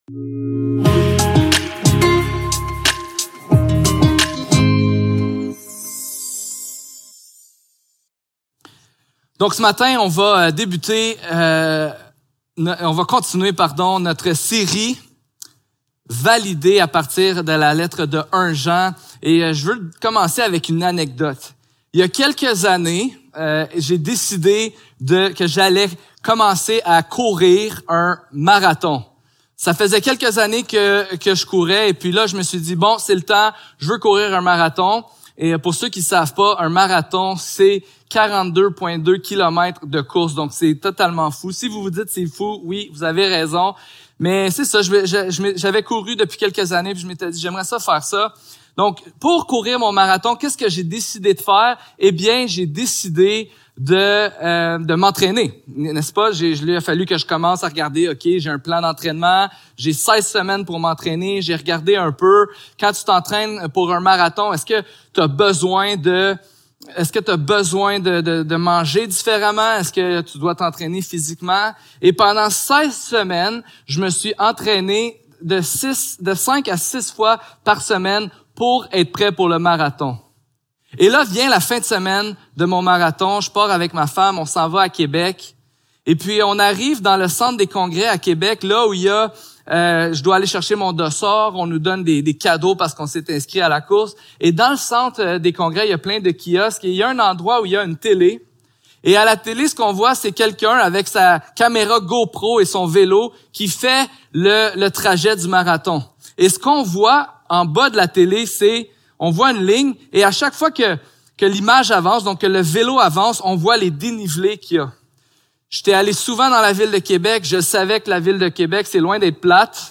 Service Type: Célébration dimanche matin